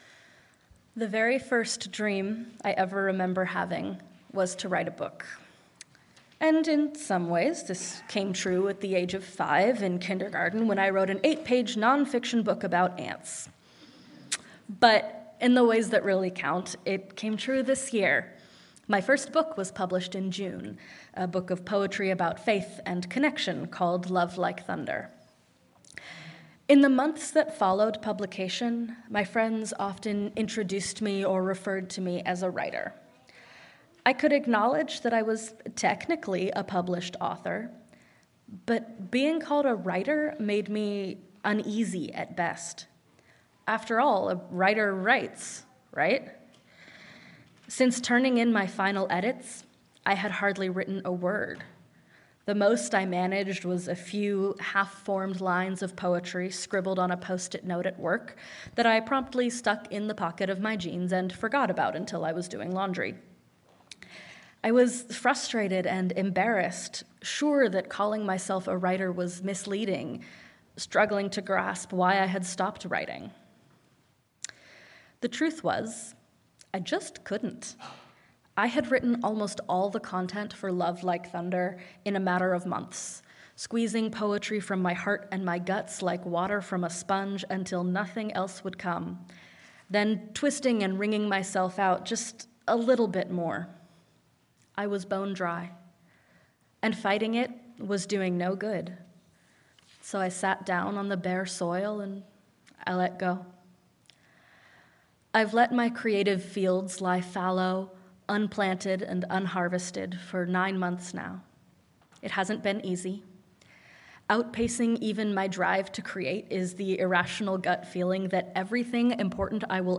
Sermon-Emergence.mp3